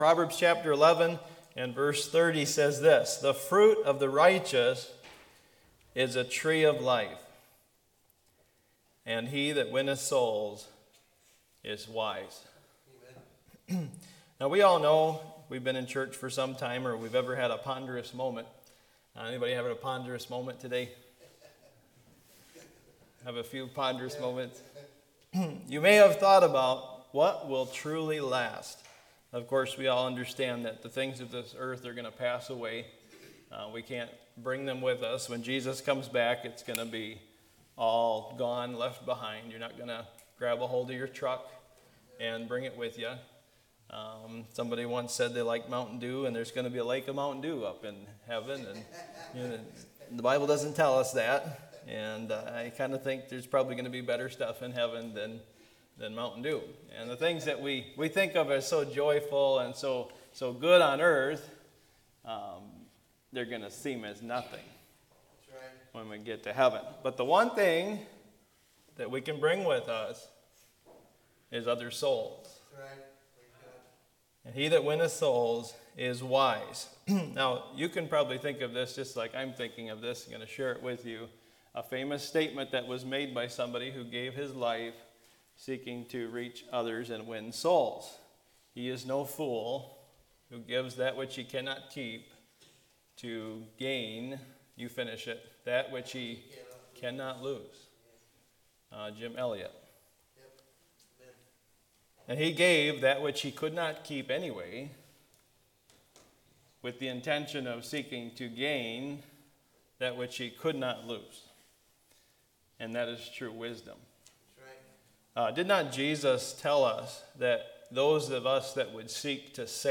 Bethlehem Baptist Church is a vibrant, growing, Great-Commission focused church located in Viroqua, WI.